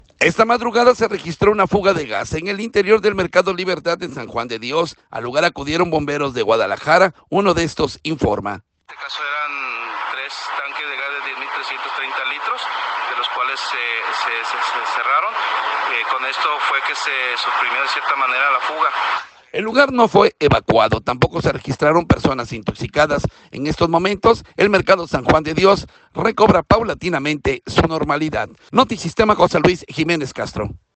Esta madrugada se registró una fuga de gas en el interior del Mercado Libertad de San Juan de Dios. Al lugar acudieron bomberos de Guadalajara, uno de estos informa.